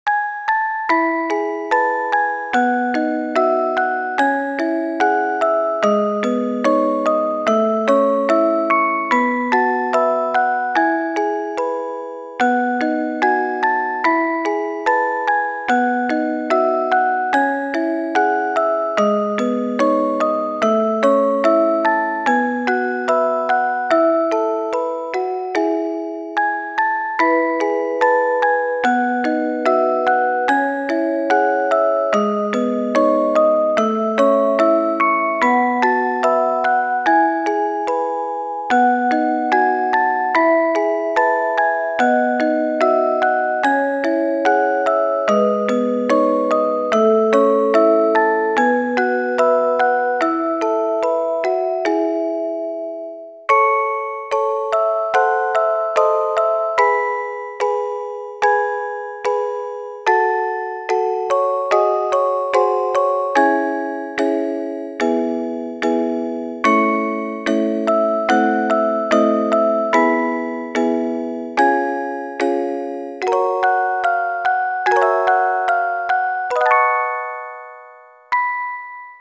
ogg(L) しっとり オルゴール スロウ
哀愁のオルゴール。